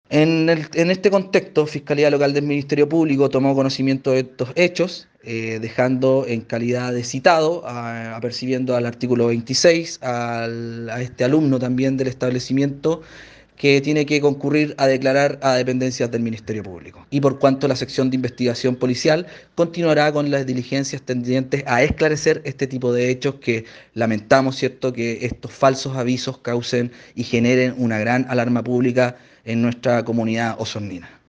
La Fiscalía Local de Osorno, citó a prestar declaración al estudiante, ante lo que el uniformado explicó que estas faltas crean falsas alertas que afectan a la comunidad.